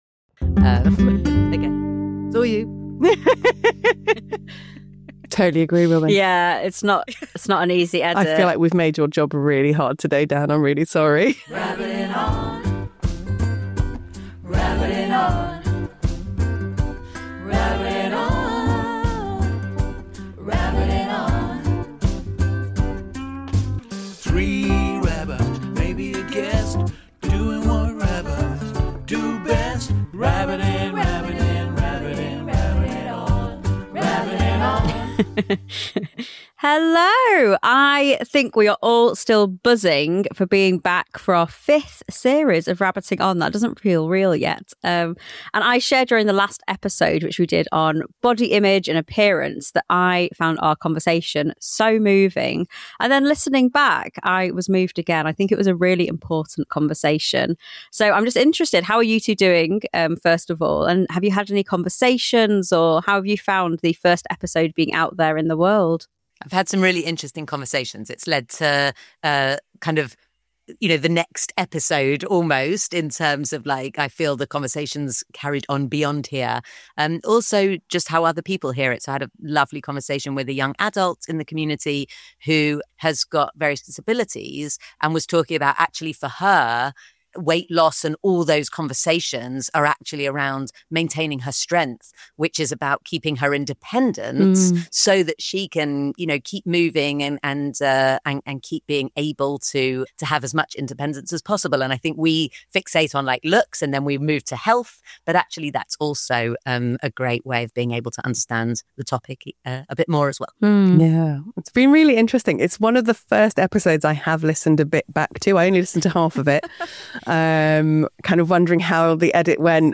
Headliner Embed Embed code See more options Share Facebook X Subscribe They say not to talk about religion or politics with friends but that's all out the window on this episode as the rabbis go for both.